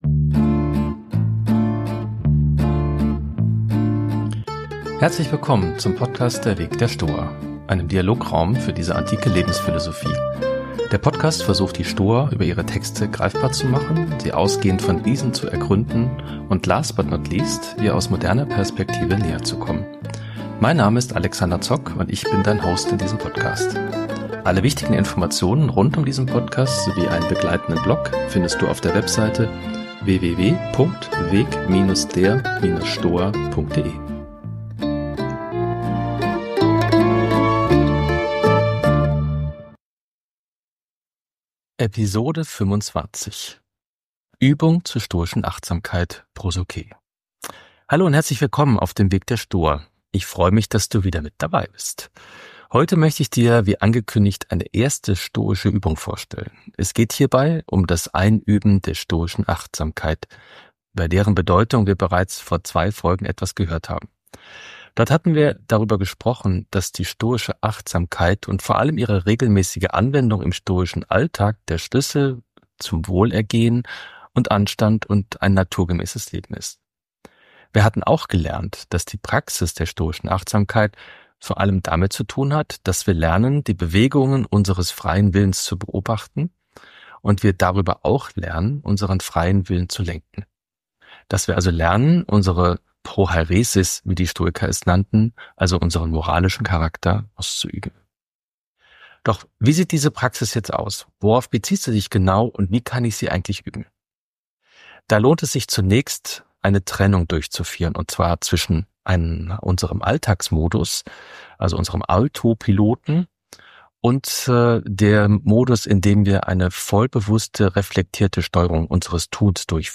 Der erste Block bietet eine kurze Einführung in die Praxis der stoischen Achtsamkeit. Im zweiten Block (ab Minute 11:30) findet sich eine angeleitete Übung zur stoischen Achtsamkeit.